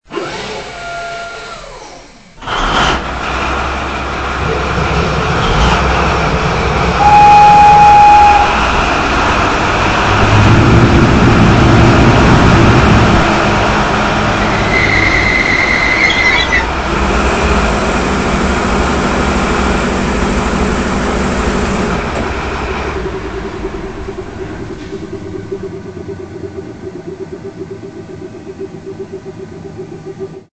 Dźwieki do lokomotyw PKP